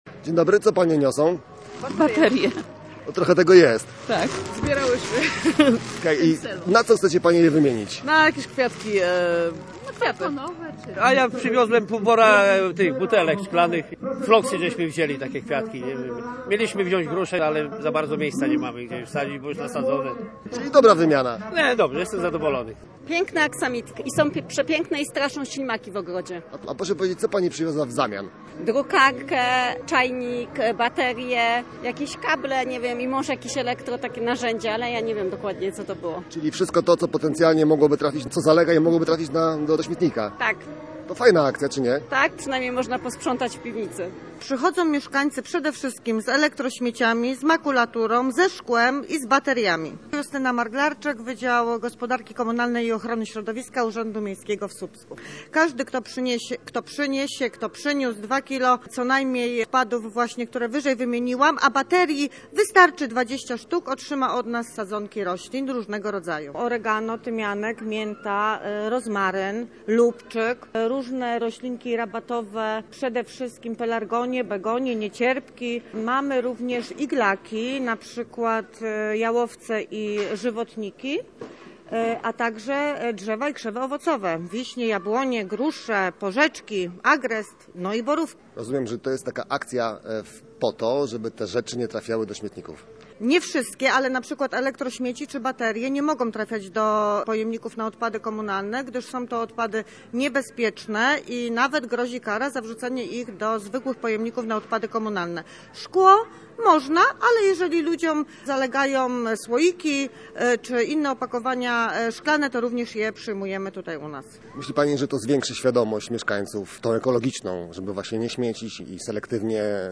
Posłuchaj relacji z wydarzenia: